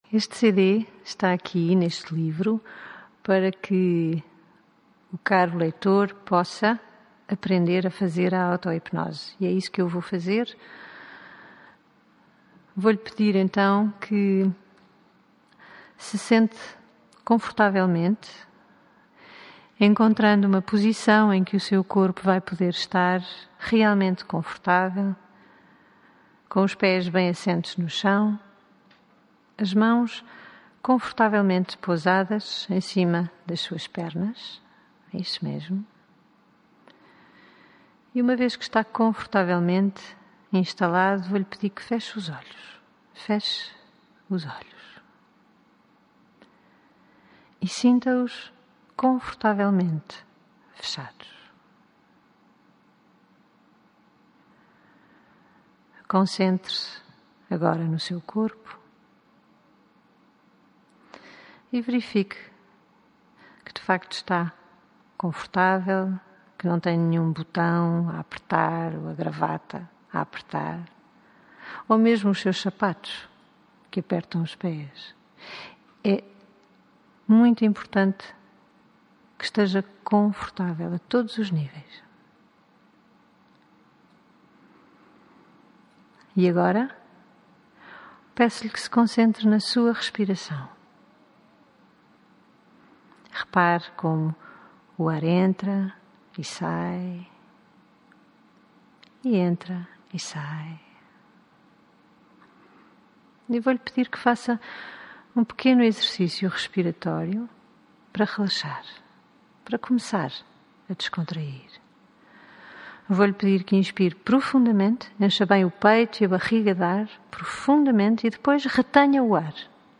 Auto Hipnose
Deixe que a minha voz o conduza através desta gravação. autohipnose_gravacao